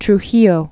(tr-hēyō)